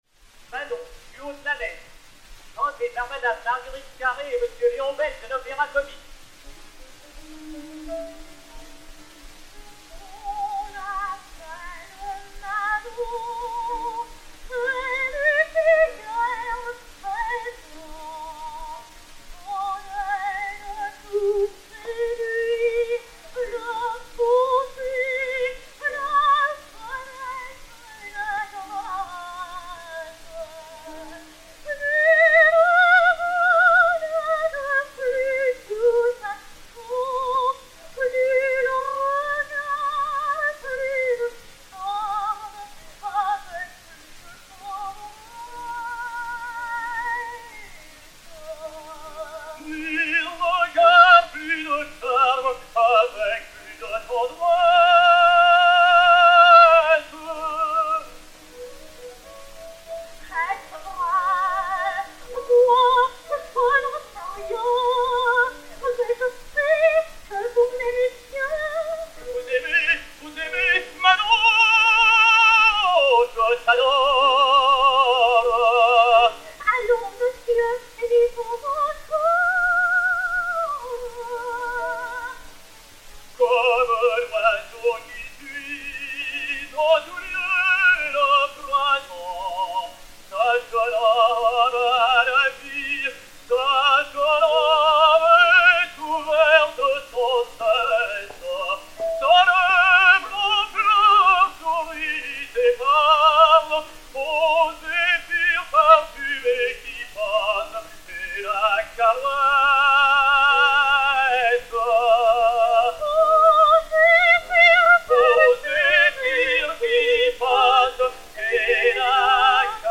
Marguerite Carré (Manon), Léon Beyle (Des Grieux) et Piano